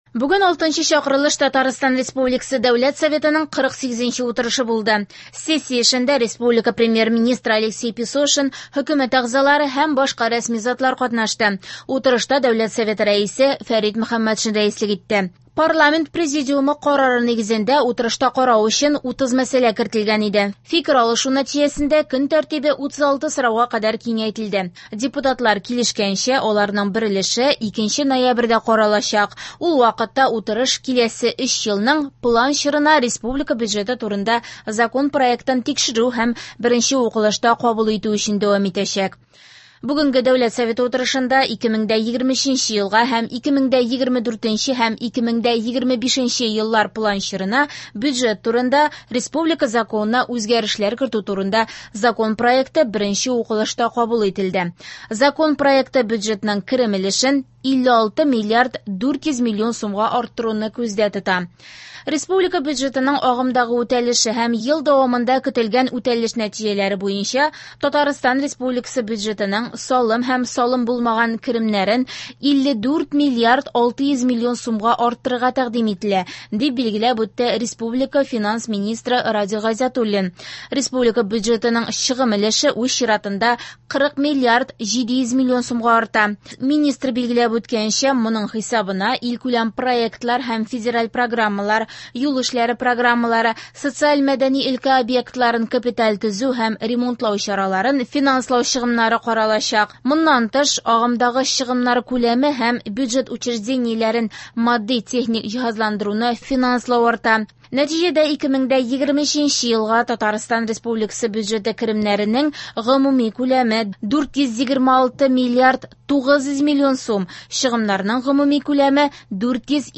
Радиоотчет (19.10.23) | Вести Татарстан
В эфире специальный информационный выпуск, посвященный 48 заседанию Государственного Совета Республики Татарстан 6-го созыва.